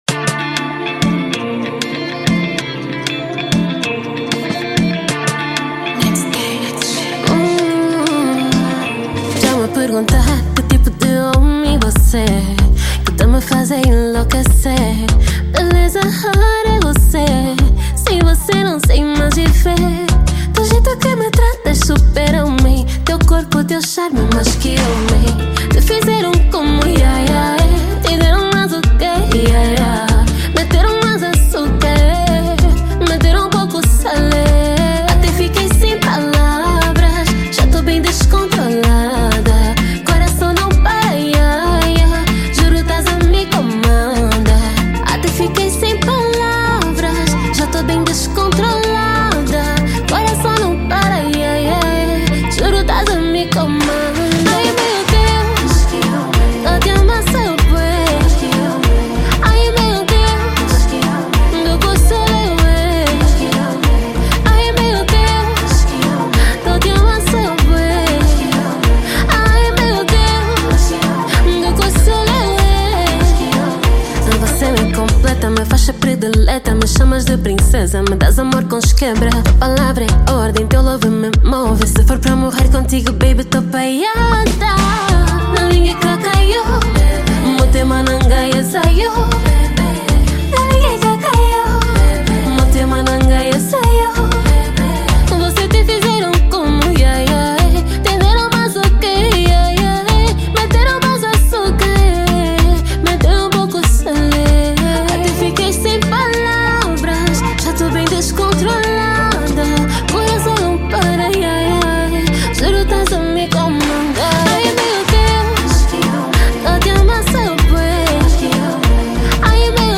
| Zouk